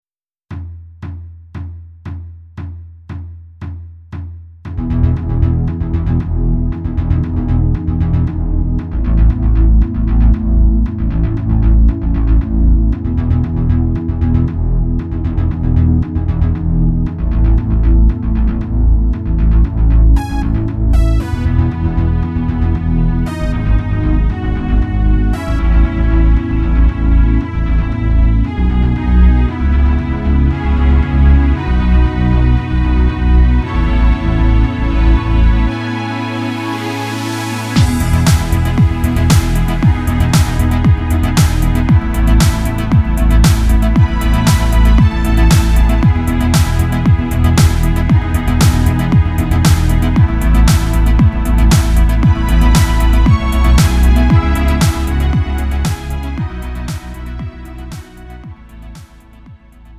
음정 -1키 3:43
장르 구분 Lite MR